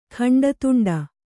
♪ khaṇḍa tuṇḍe